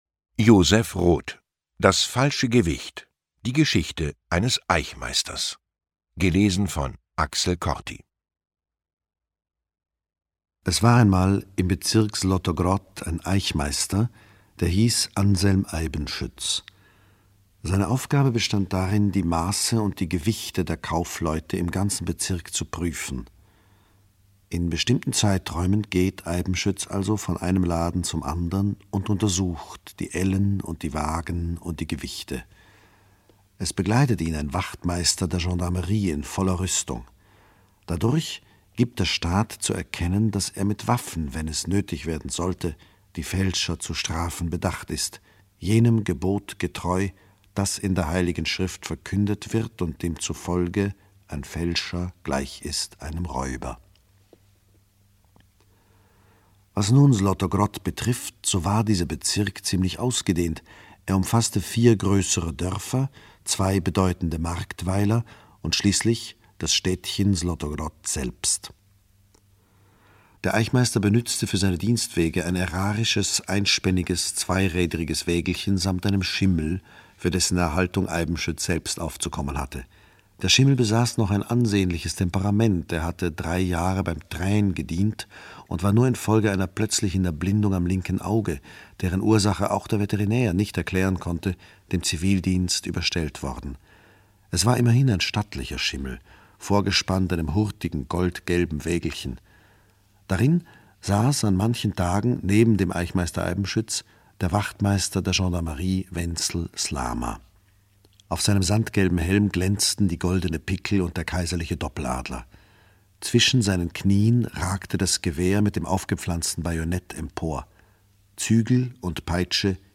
Ungekürzte Lesung mit Axel Corti (1 mp3-CD)
Axel Corti (Sprecher)